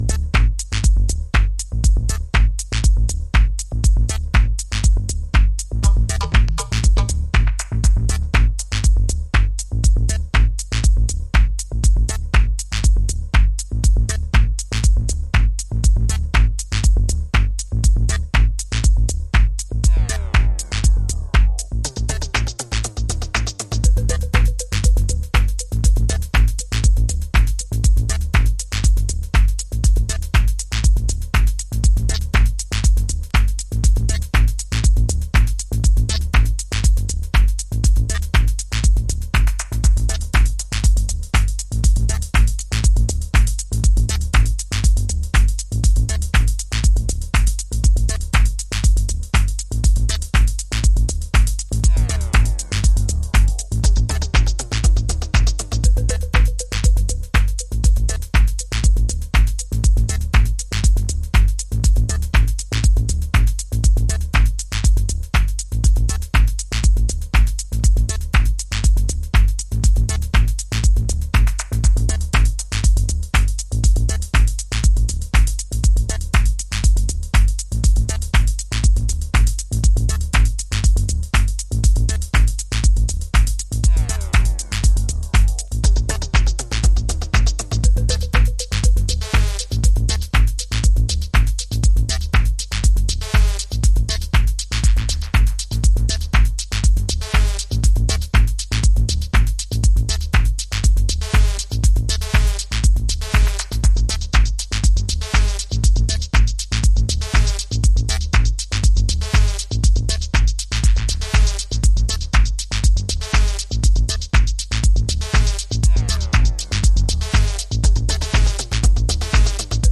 マッド・エフェクト、空間をドライヴするブギーグルーヴのアシッディーハウスリミックス。